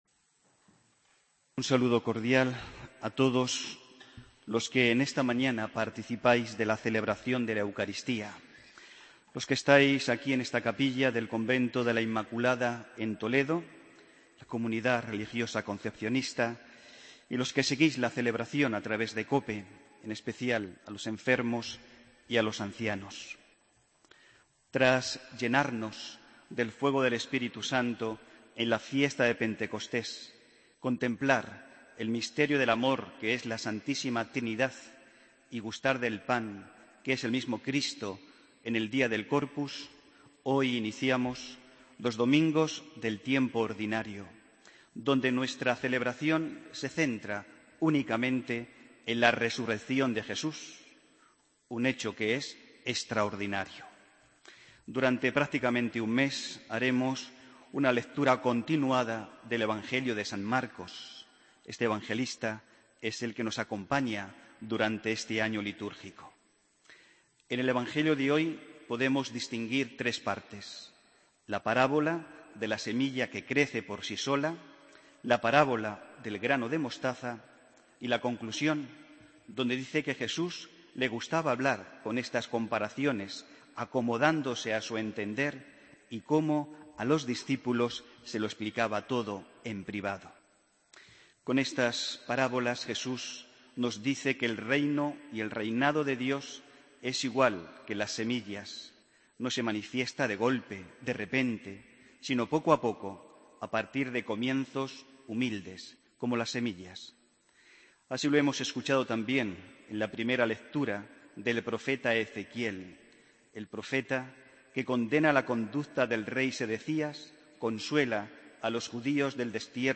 Homilía, domingo 14 de junio de 2015